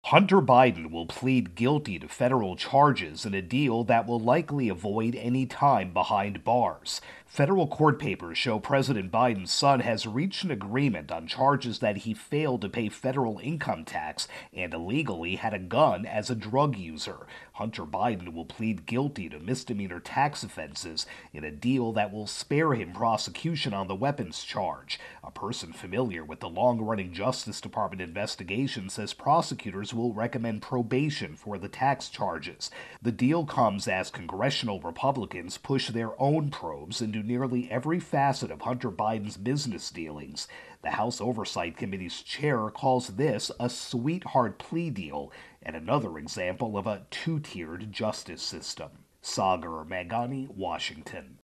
reports on Hunter Biden plea deal